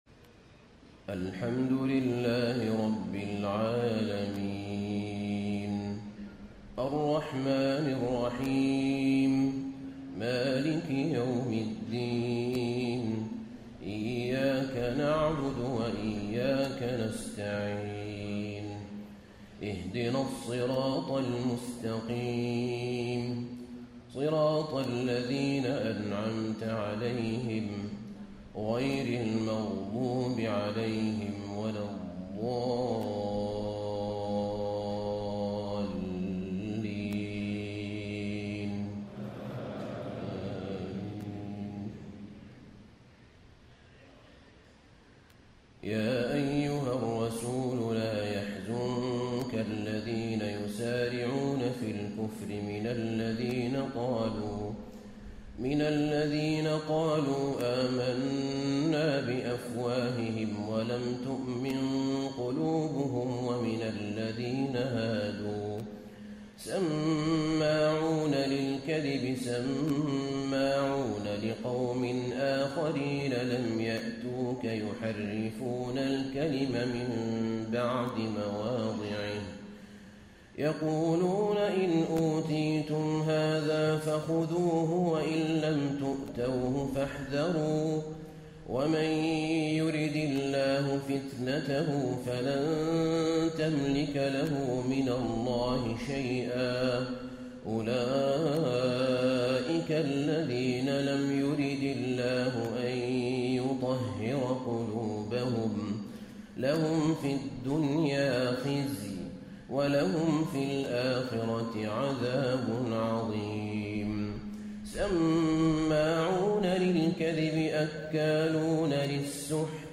تهجد ليلة 26 رمضان 1435هـ من سورة المائدة (41-81) Tahajjud 26 st night Ramadan 1435H from Surah AlMa'idah > تراويح الحرم النبوي عام 1435 🕌 > التراويح - تلاوات الحرمين